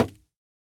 Minecraft Version Minecraft Version latest Latest Release | Latest Snapshot latest / assets / minecraft / sounds / block / bamboo_wood / break5.ogg Compare With Compare With Latest Release | Latest Snapshot